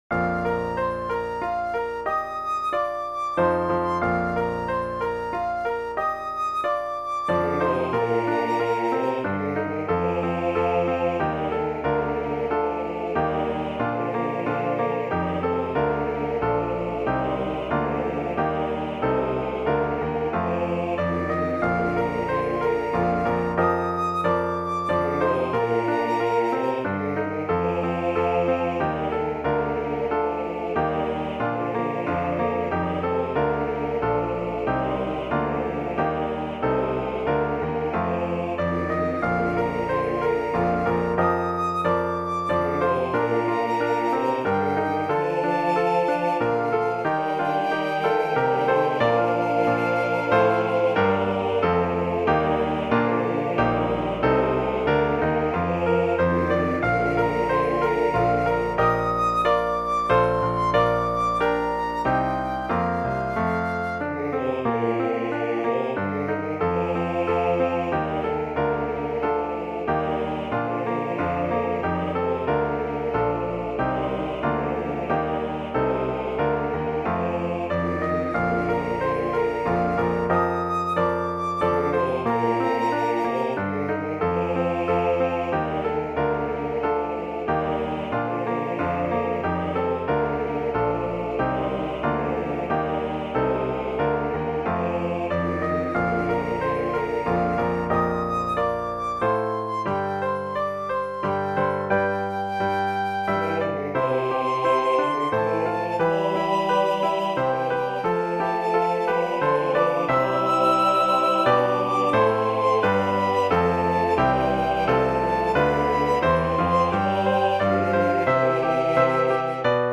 Voicing/Instrumentation: TB We also have other 61 arrangements of " The First Noel ".
Flute Optional Obbligato/Flute Accompaniment